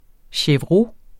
chevreau substantiv, intetkøn Bøjning -et Udtale [ ɕεvˈʁo ] Oprindelse fra fransk chevreau 'gedekid', af chèvre 'ged' Betydninger garvet gedeskind, som især anvendes til handsker og overlæder på sko Rapportér et problem fra Den Danske Ordbog Den Danske Ordbog .